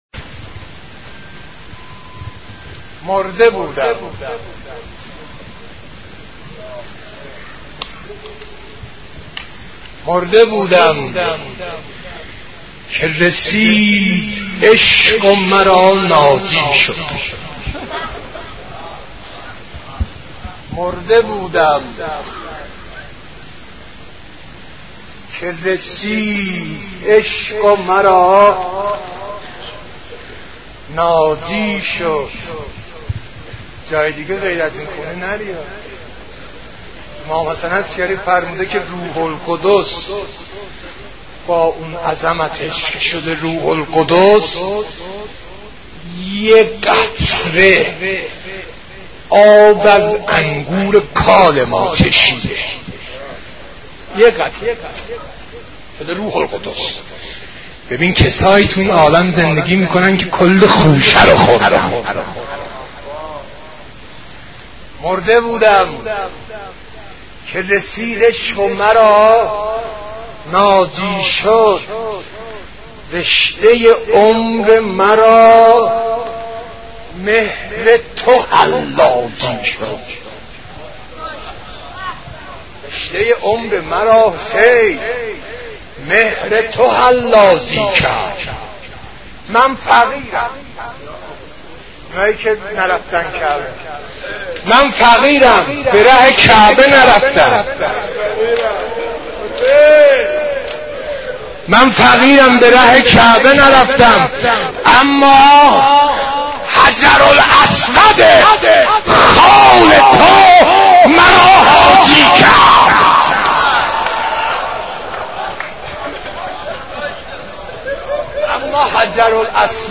حضرت عباس ع ـ مداحی 1